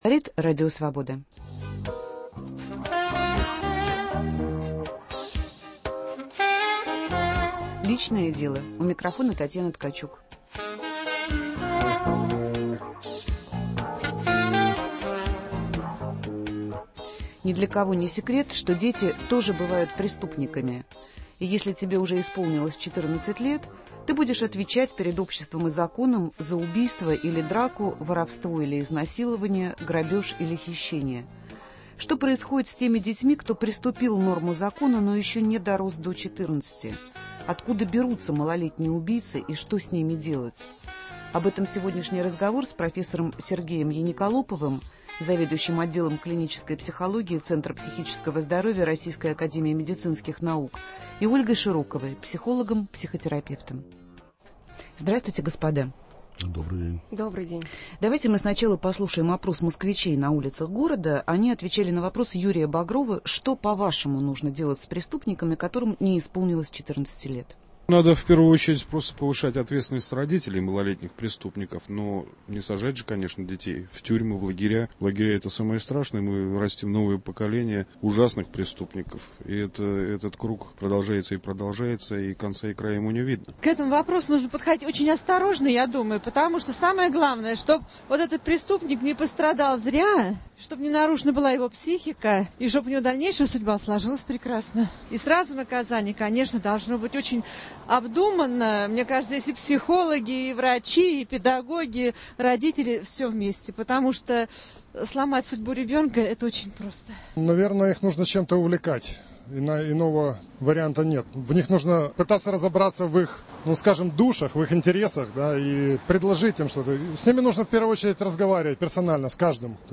3 ноября в прямом эфире мы будем говорить о детях, совершающих преступления. Откуда берутся 12-летние убийцы?